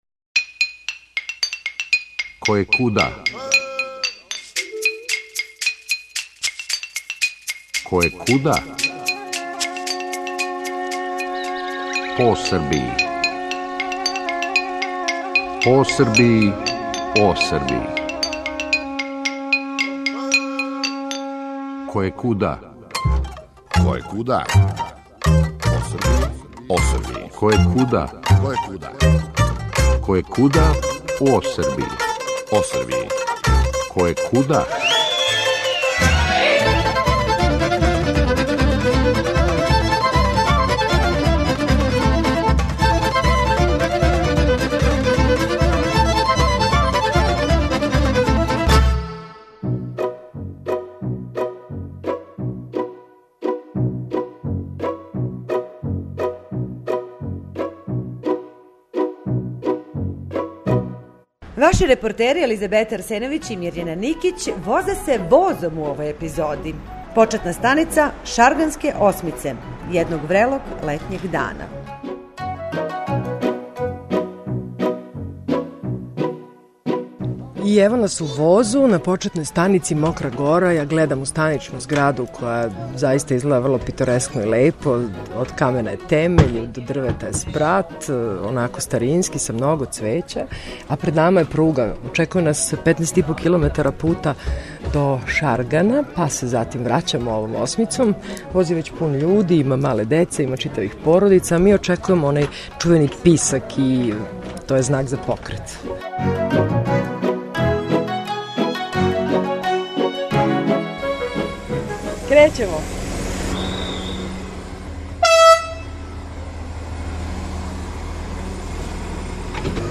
Део ове пруге на релацији од станице Шарган витаси до Мокре горе, који представља јединствено градитељско дело, отргнуто је од заборава и надалеко чувеном Шарганском "осмицом" поново одјекује клапарање вагона и весели жамор путника у возу "Носталгија".